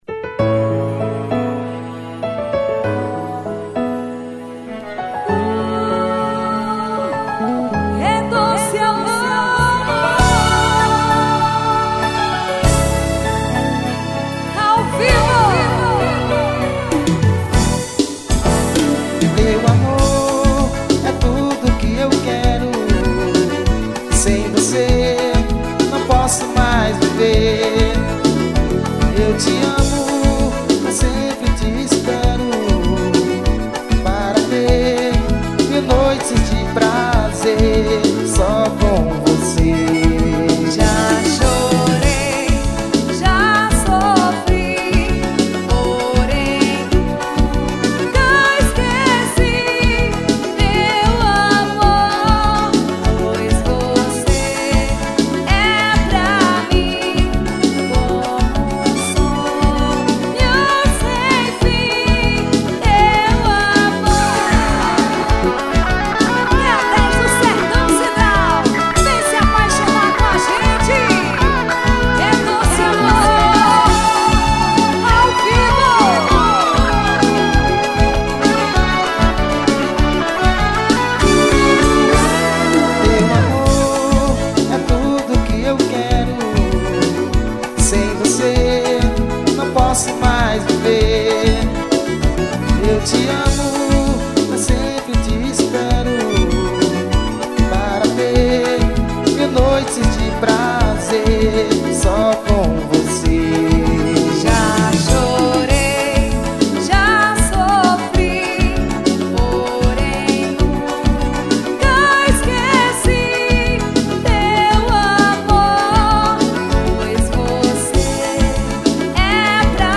1852   02:40:00   Faixa:     Forró